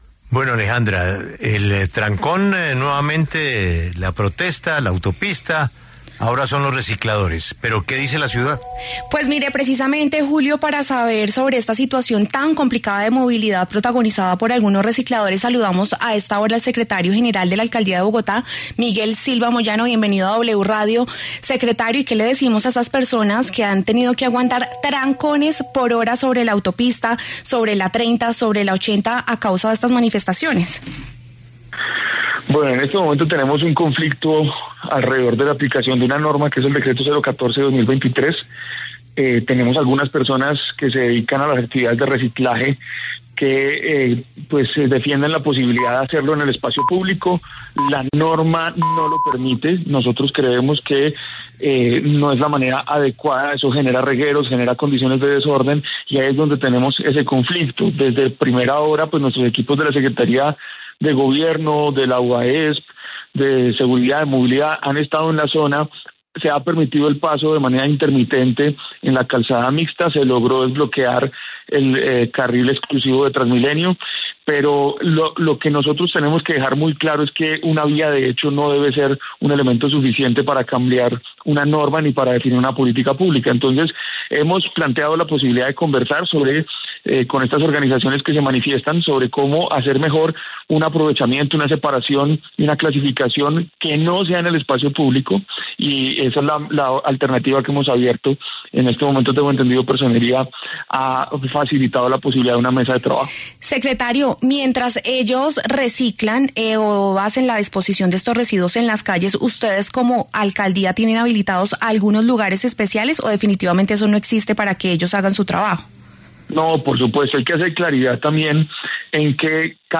Miguel Silva Moyano, secretario general de la Alcaldía de Bogotá, pasó por los micrófonos de La W para hablar de las jornadas de manifestaciones que se han reportado este lunes, 22 de diciembre y han colapsado la movilidad de la capital.